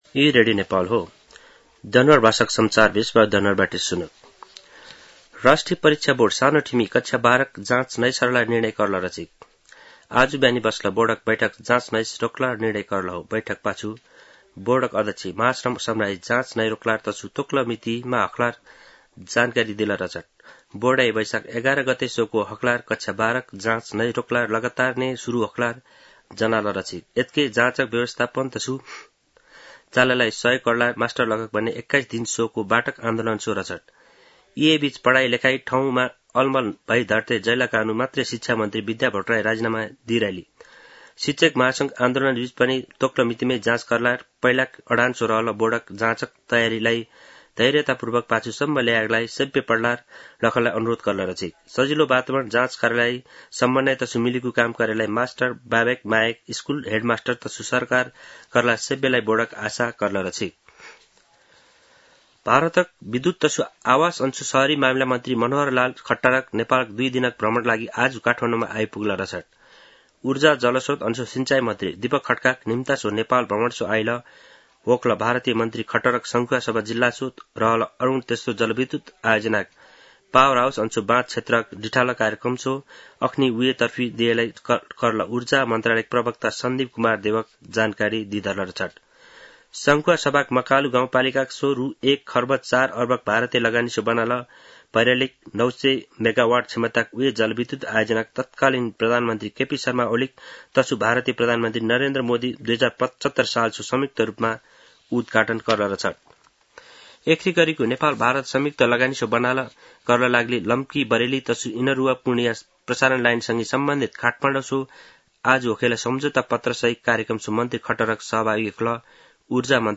दनुवार भाषामा समाचार : ९ वैशाख , २०८२